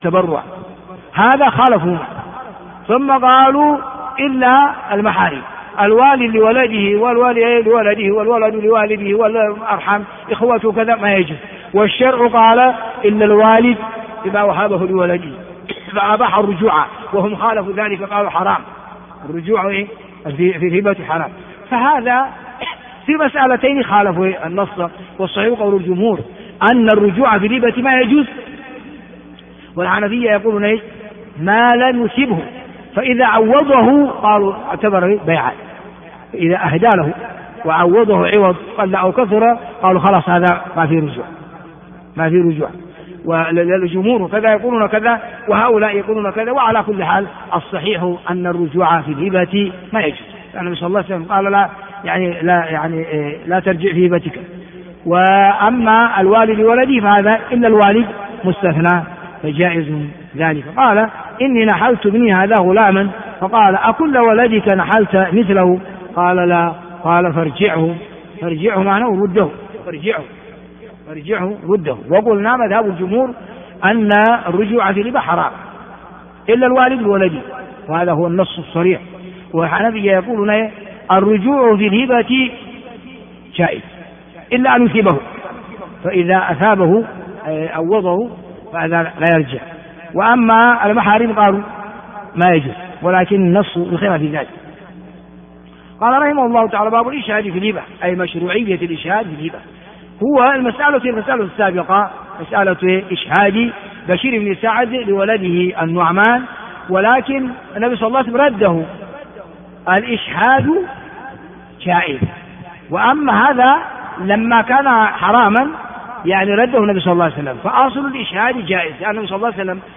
الدرس 176